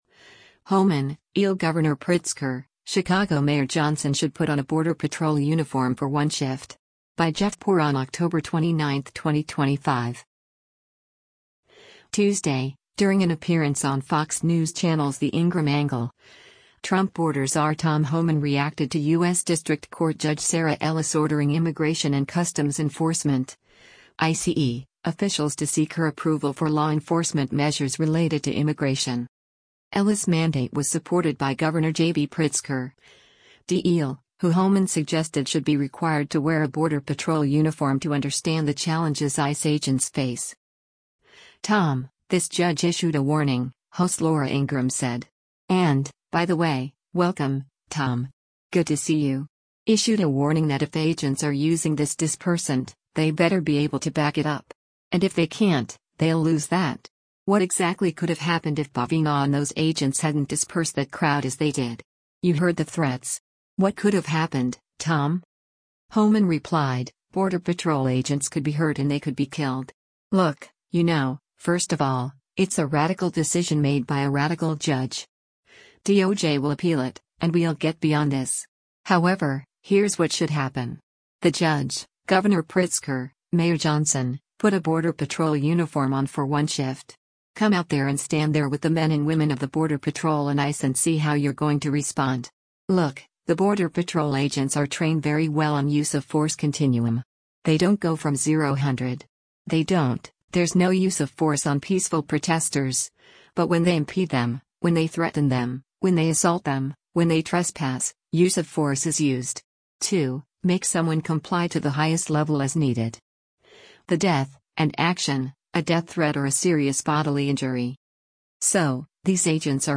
Tuesday, during an appearance on Fox News Channel’s “The Ingraham Angle,” Trump border czar Tom Homan reacted to U.S. District Court Judge Sarah Ellis ordering Immigration and Customs Enforcement (ICE) officials to seek her approval for law enforcement measures related to immigration.